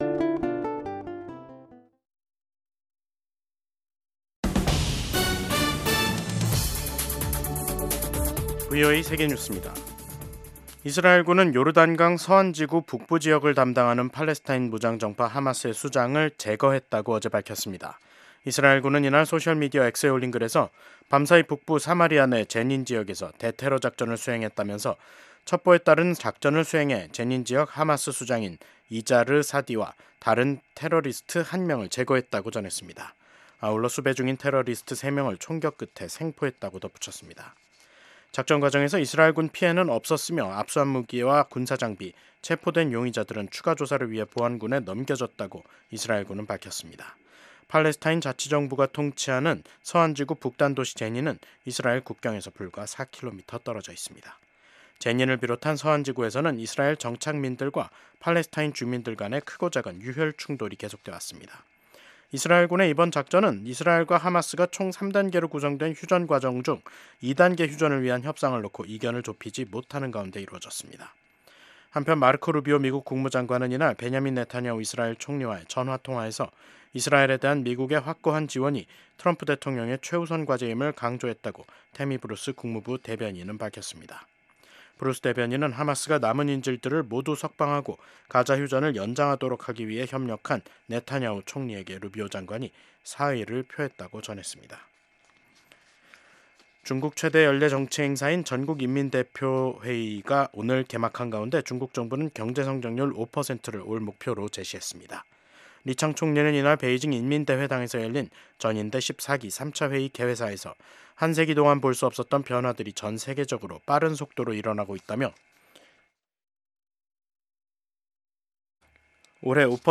VOA 한국어 간판 뉴스 프로그램 '뉴스 투데이', 2025년 3월 5일 3부 방송입니다. 도널드 트럼프 미국 대통령이 2기 행정부 출범 이후 첫 의회 상하원 합동회의 연설에서 ‘미국이 돌아왔다’고 강조했습니다. 트럼프 대통령은 이번 상하원 합동회의 연설에서 북한은 언급하지 않았고, 동맹국인 한국에 대해서는 부당하게 높은 대미 관세를 부과하고 있다고 밝혔습니다.